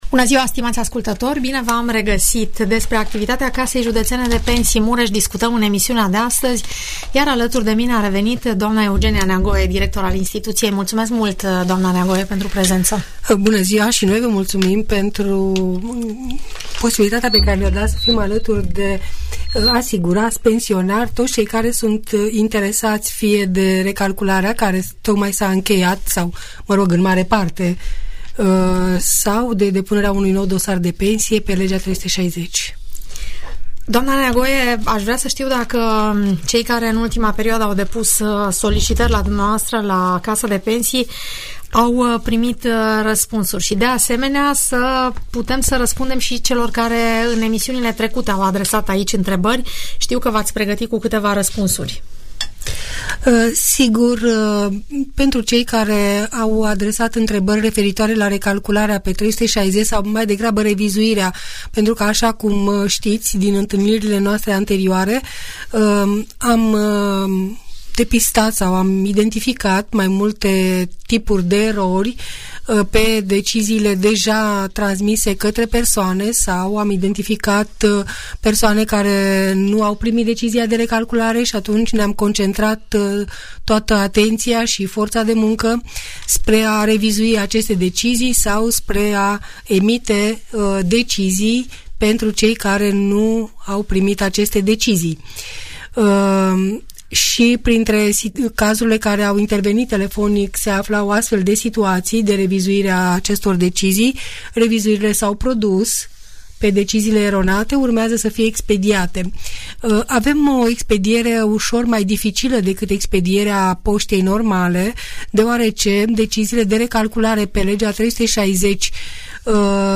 Urmărește dialogul moderat